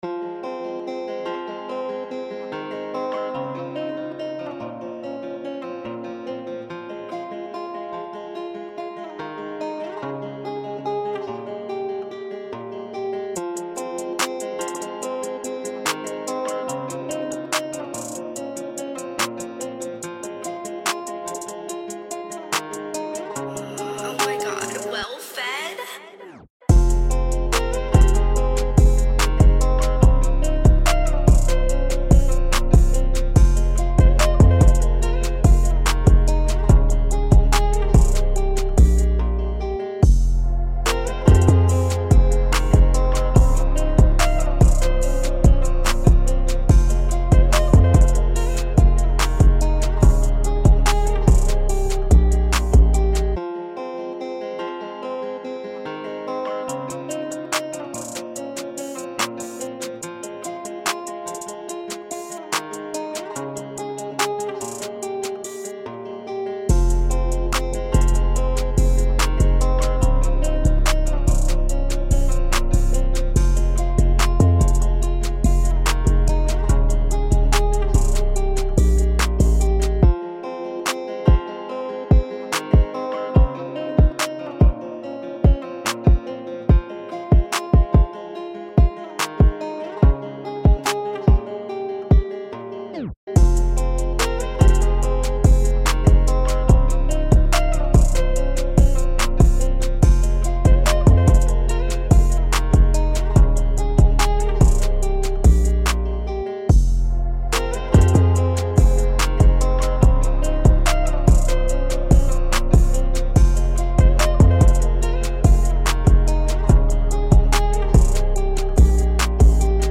/ فیلم برچسب‌ها: sad song sad lofi beats sad اهنگ بیت غمگین دیدگاه‌ها (اولین دیدگاه را بنویسید) برای ارسال دیدگاه وارد شوید.